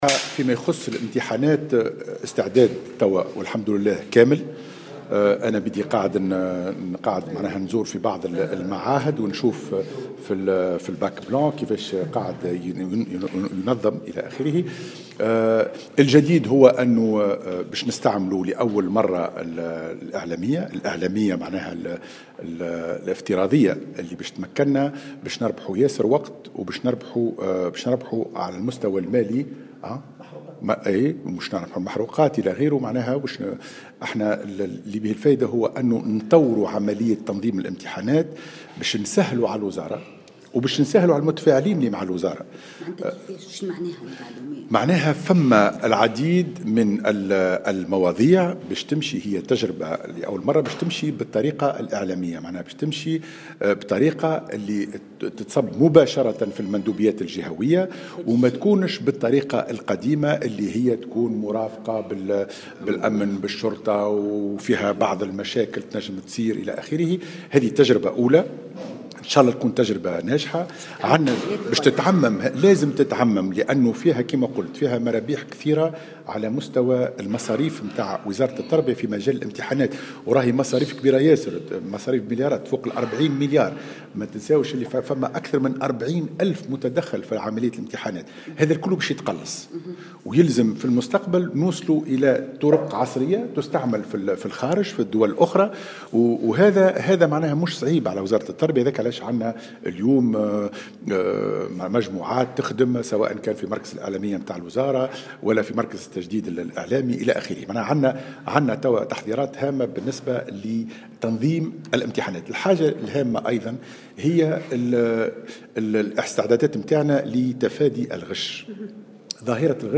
على هامش الملتقى الاول حول الاعلام و التعامل مع الشأن التربوي